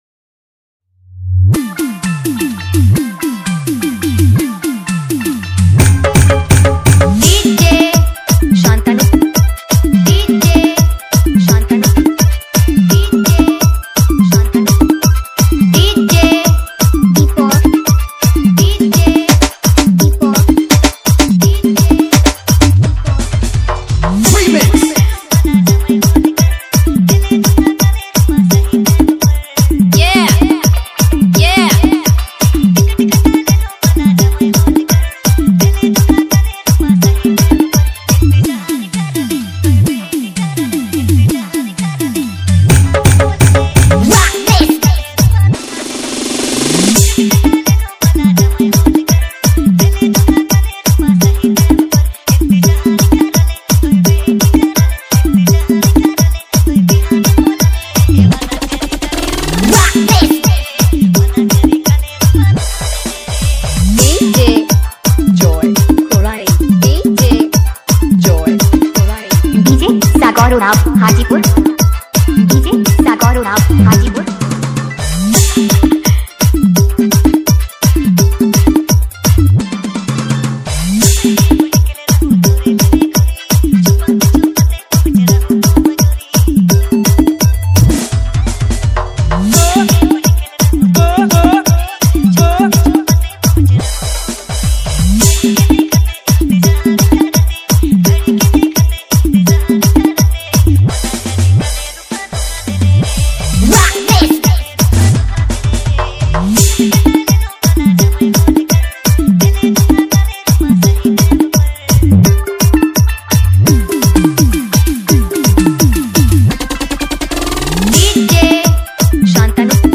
Remix Nagpuri Dj Songs Mp3 2022